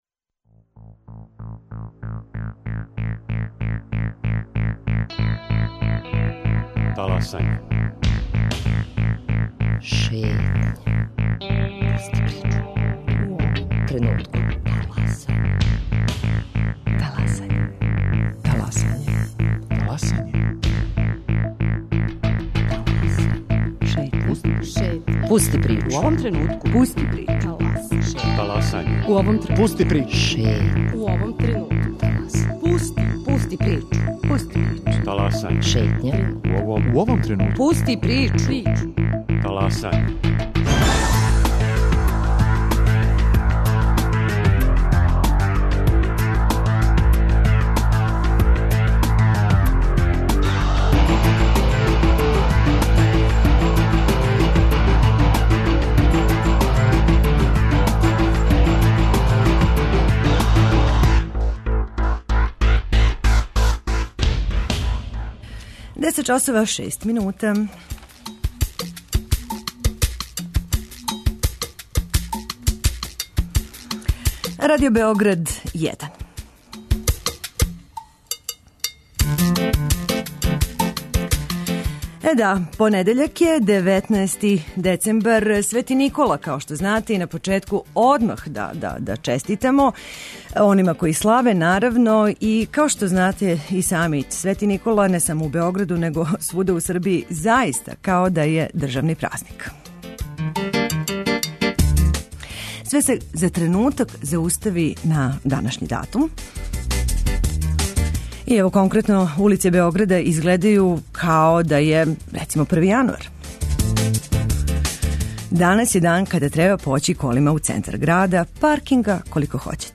У првом сату Таласања повешћемо слушаоце најпре у Ниш, нудећи репортерску слику града на Нишави на велики празник - светог Николу.
И овог понедељка, отварамо телефоне за наше слушаоце које ћемо питати - Да ли посмртни остаци великог научника Николе Тесле и даље треба да остану похрањени у урни, у његовом музеју у Београду.